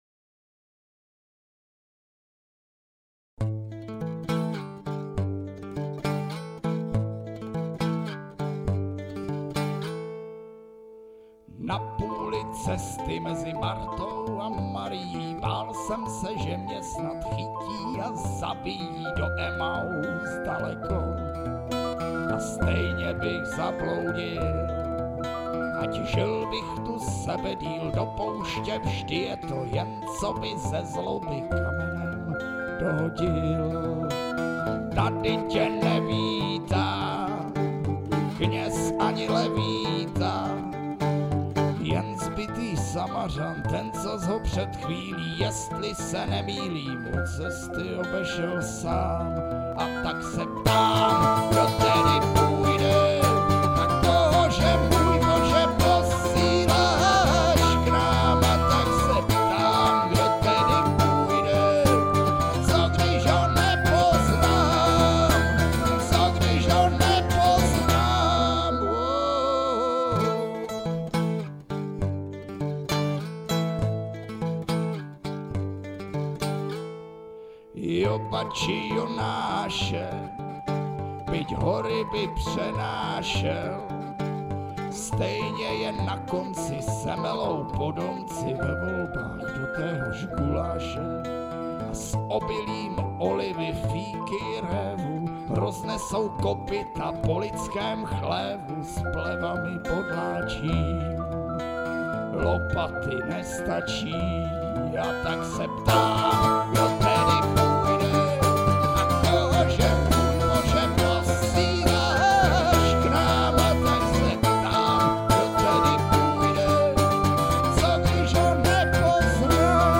Takže závěr: Písnička Kdo půjde - s tvýma klávesama se mi moc líbila!
doprovodný "majstrštyk" k naslechnutí .